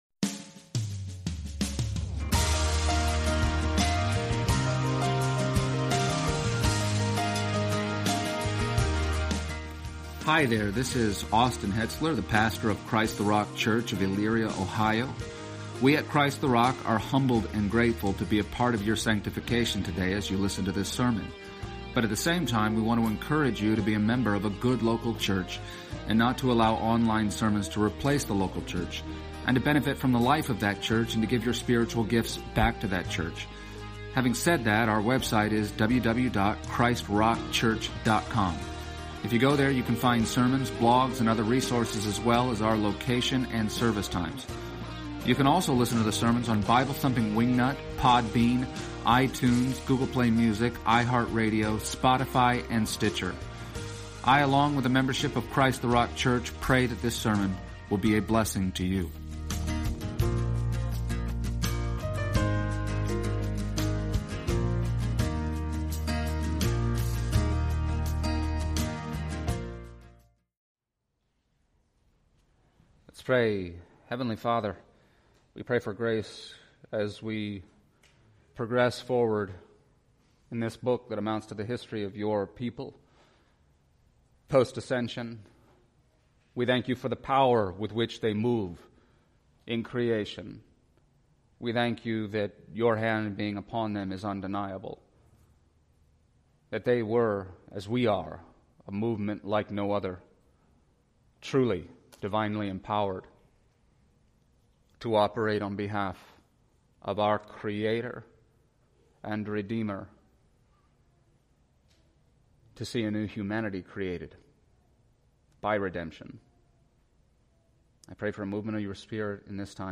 Exposition of the Book of Acts Passage: Acts 5:12-21 Service Type: Sunday Morning %todo_render% « Ananias and Saphira